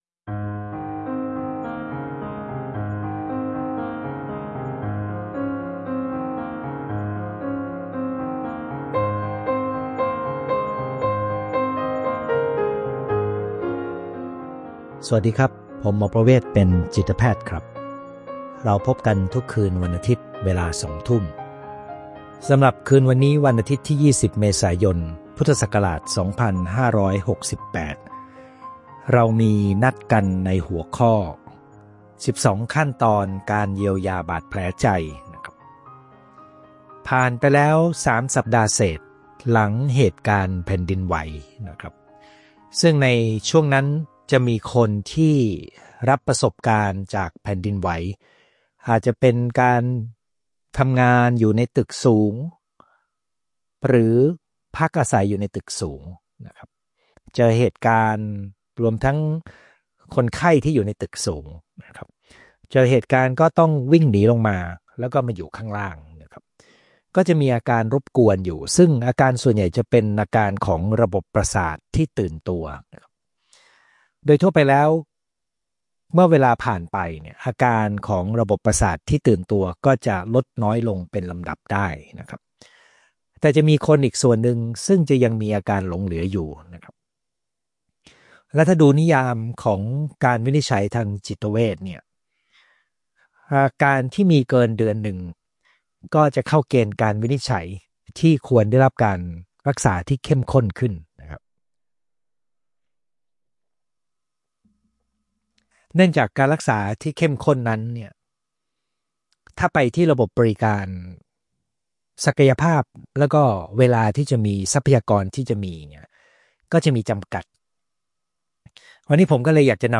ไลฟ์วันอาทิตย์ที่ 31 มีนาคม 2567 เวลาสองทุ่ม